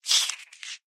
mob / silverfish / kill.ogg